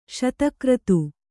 ♪ śata kratu